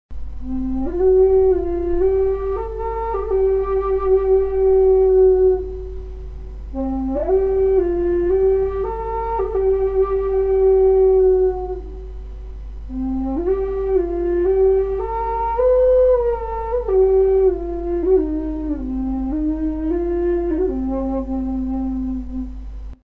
Флейта-посох low C (Walking Stick Flute)
Флейта-посох low C (Walking Stick Flute) Тональность: C
Флейта имеет пять игровых отверстий. Строй стандартная минорная пентатоника. Несмотря на размер, флейта звучит в среднем диапазоне.